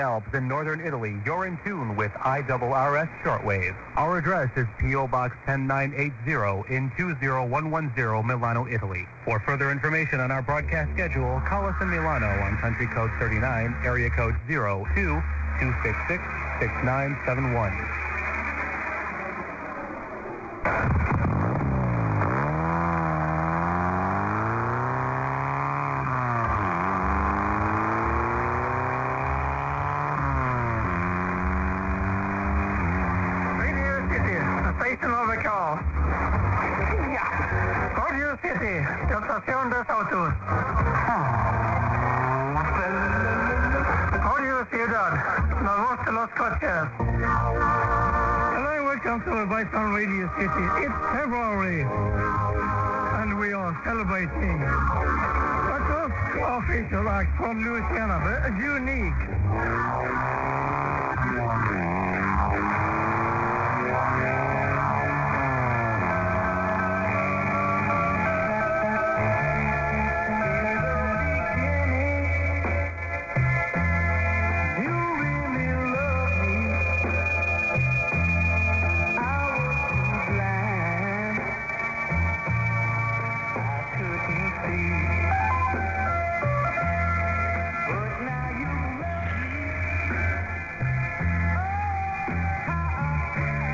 I am attaching a recording taken in Ukraine last Saturday on our new frequency of 1323 kHz AM/Medium Wave at 22:00 Central European Time (23:00 or 11 PM local time in Ukraine). The recording is for Radio City, a DJ music program in English, but it shows how strong we can cover the conflict area.